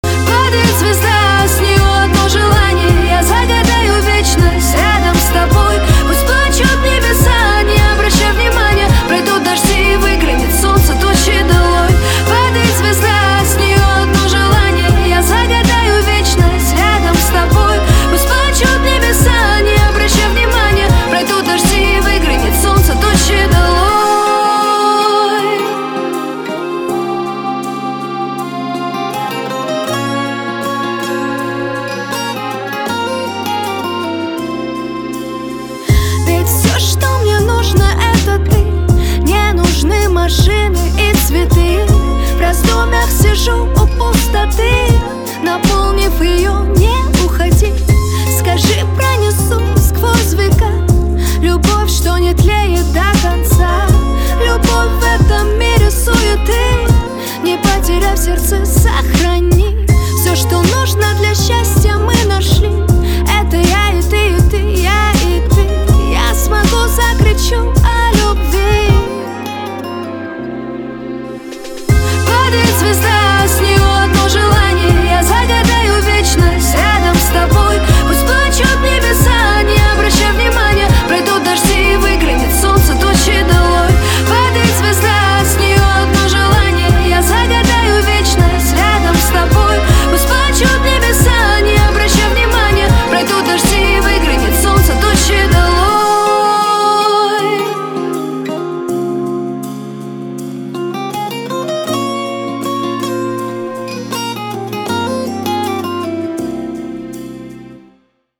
• Качество: 320, Stereo
поп
душевные
романтичные
красивый женский вокал